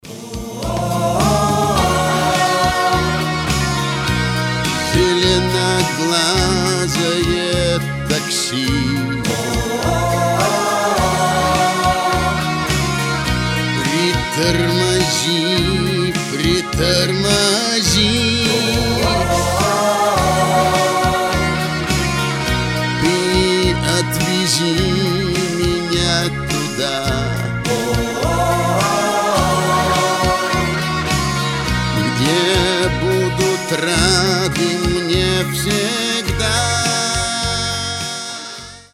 Медленные
New wave
Ретро